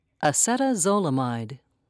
(a-set-a-zole'a-mide)